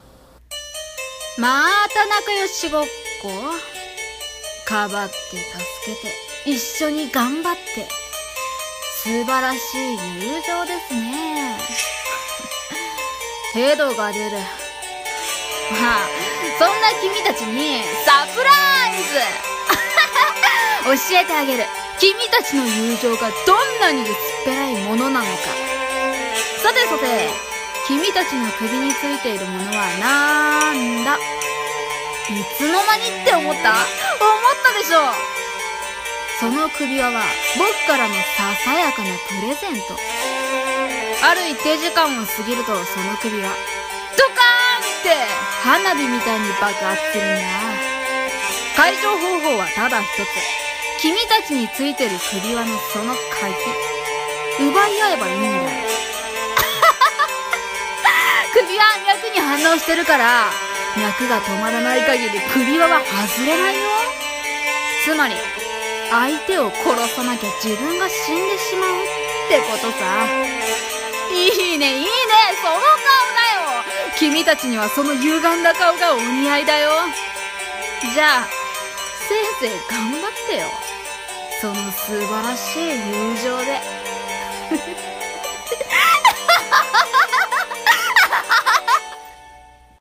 【一人声劇】
【悪役】